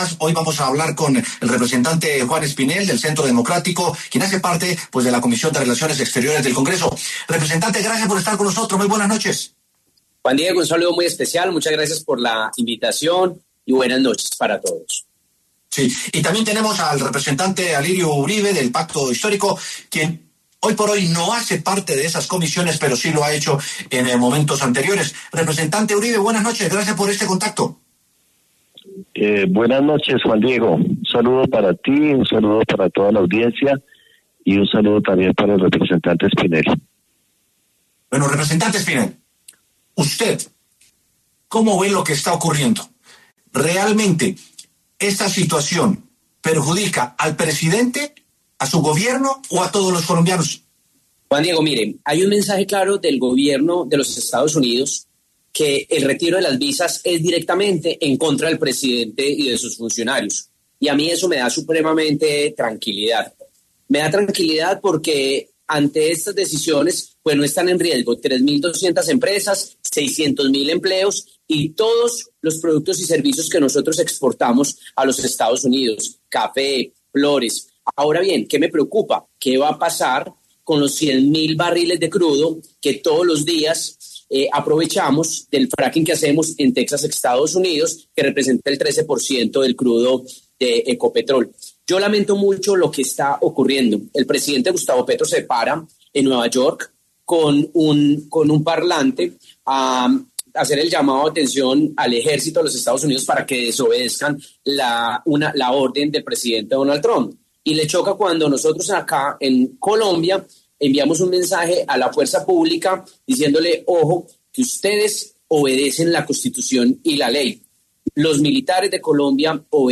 Para debatir el tema de las relaciones entre los Estados Unidos y Colombia, pasaron por los micrófonos de W Sin Carreta los congresistas Juan Espinel, del Centro Democrático, y Alirio Uribe, del Pacto Histórico.